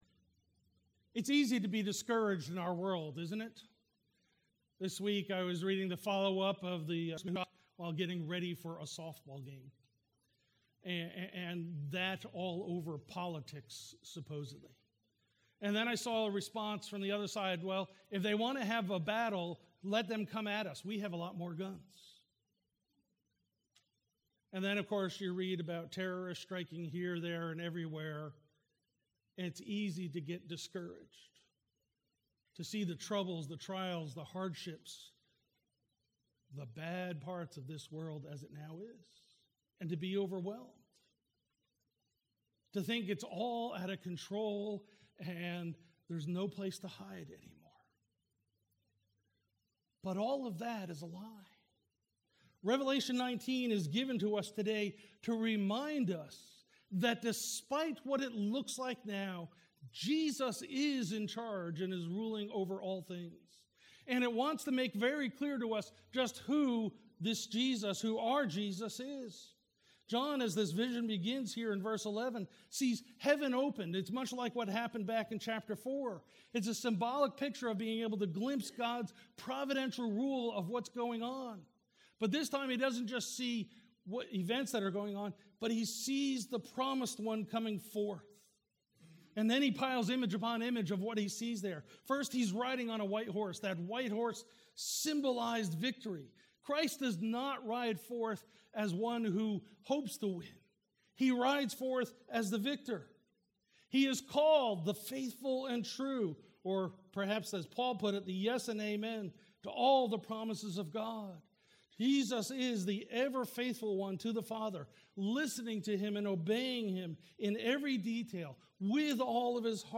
Trinity Presbyterian Church Sermons